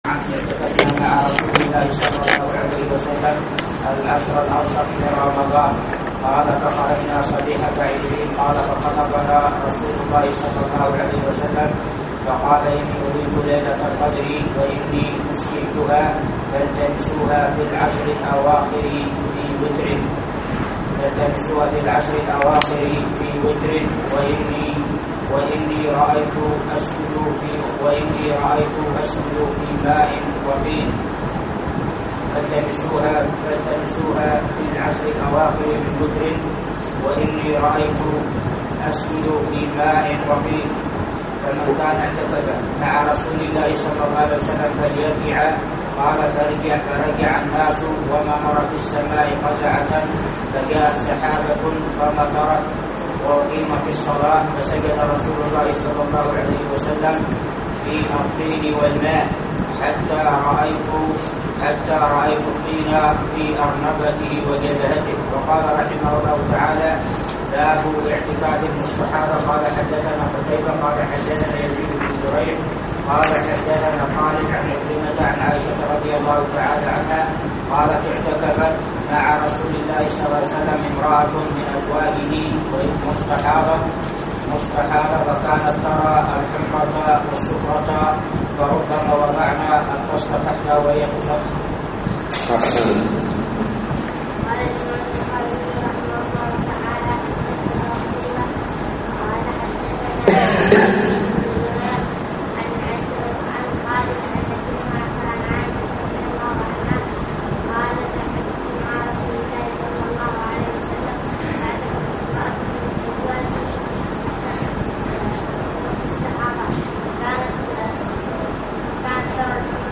الدرس السابع : باب زيارة المرأة زوجها في اعتكافه وباب هل يدرأ المعتكف عن نفسه وباب من خرج من اعتكافه عند الصبح